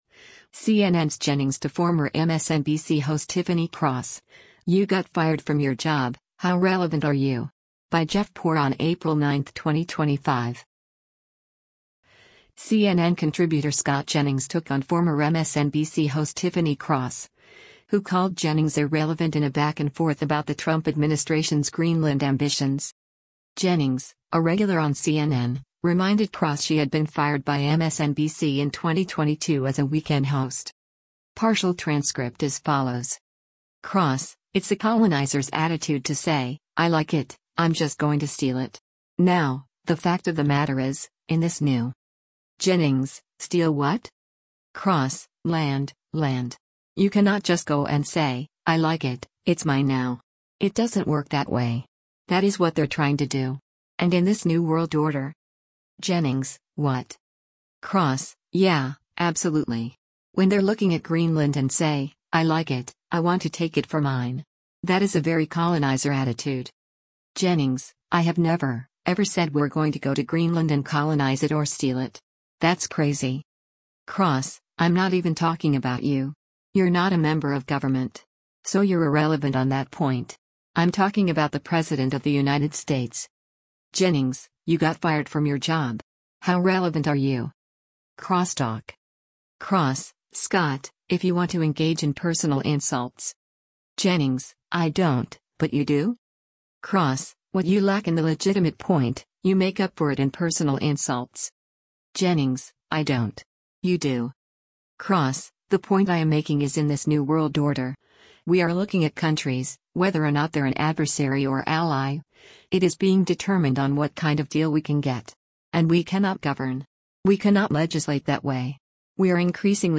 CNN contributor Scott Jennings took on former MSNBC host Tiffany Cross, who called Jennings “irrelevant” in a back-and-forth about the Trump administration’s Greenland ambitions.